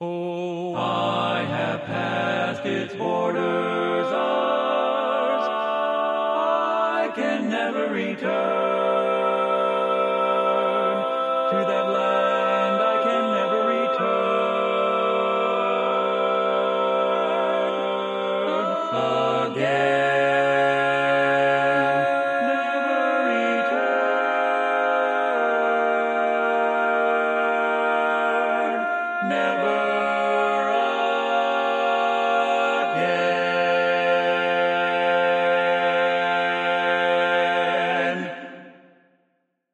Key written in: F Major
Type: Barbershop